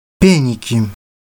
Peniki (Russian: Пе́ники, romanized: Péniki, IPA: [ˈpʲenʲɪkʲɪ]